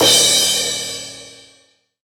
DJP_PERC_ (1).wav